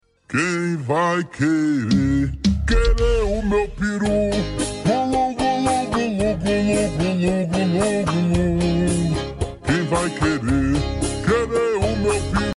2024-12-20 00:49:23 Gênero: Trap Views